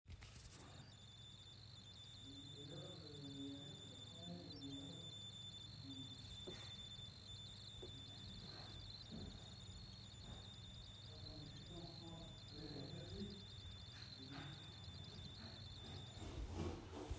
Tout fonctionne parfaitement bien (chaud, froid), mais même à l'arrêt les cassettes font un bruit électronique très désagréable.
Le son est enregistré avec un téléphone à une distance d'environs 1cm de la partie électronique de la cassette.
forum consulter ce sujet Problème sifflement électrique UI hors marche
son-electronique-.mp3